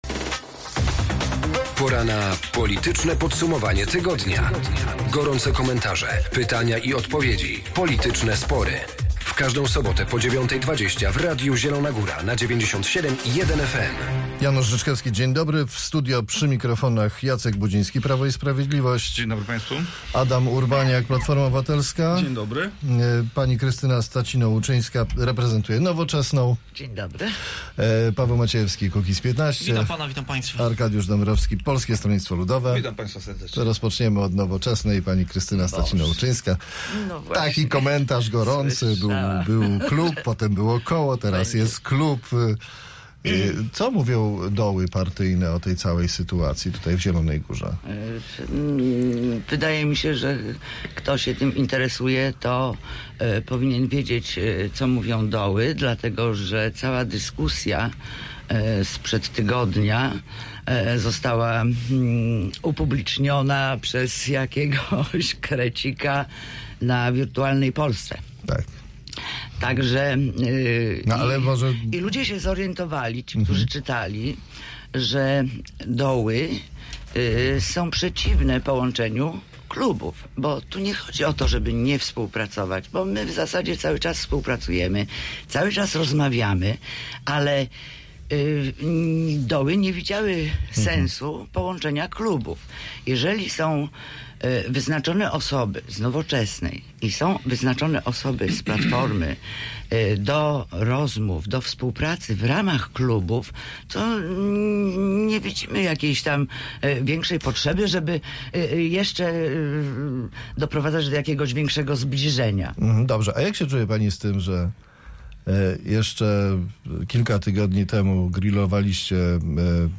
W Politycznym Podsumowaniu Tygodnia rozmawiali: